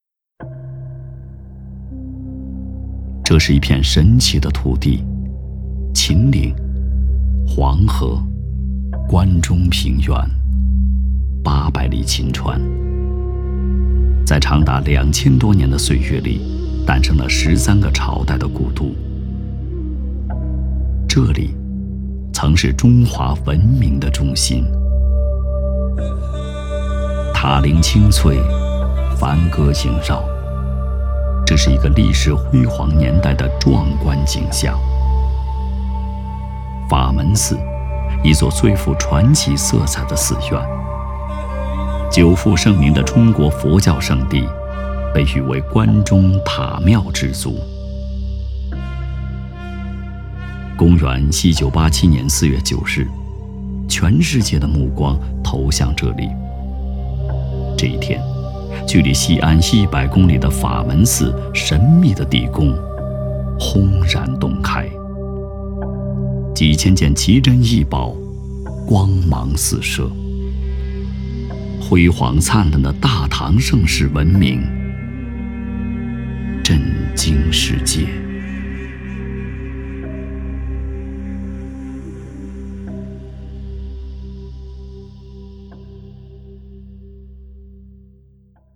纪录配音--声音作品--海滨声音艺术学院